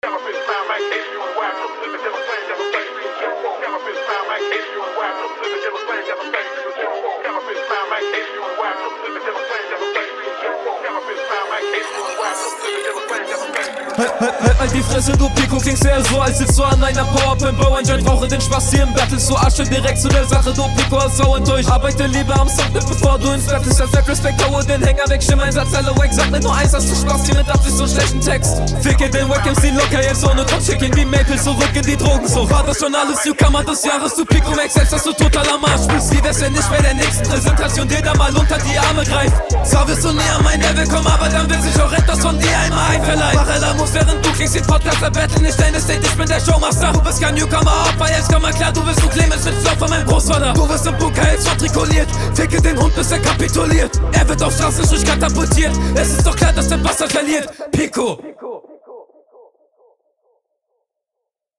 Insgesamt recht Rund geflowt.
Der Flow ist ganz cool und ich mag den Stimmeinsatz in Bezug auf die Energie, …